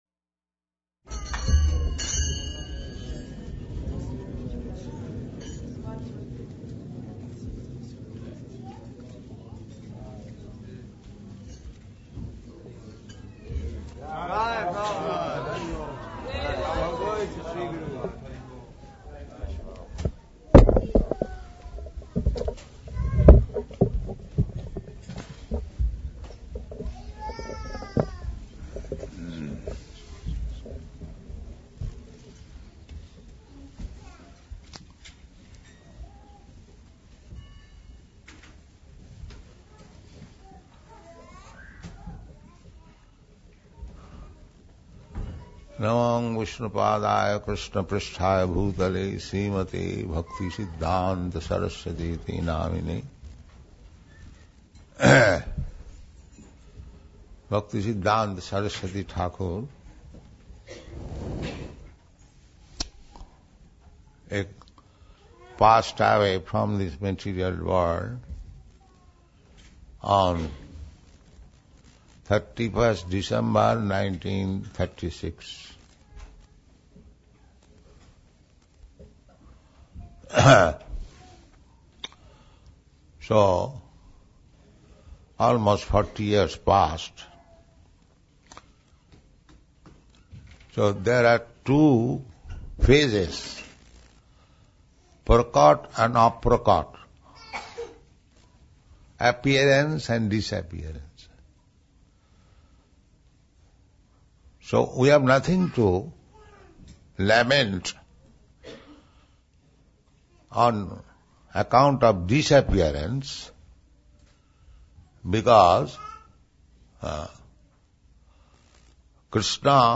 Srila Prabhupada Lecture on Srila Bhaktisiddhanta Sarasvati Prabhupada’s Disappearance Day, Los Angeles, December 13, 1973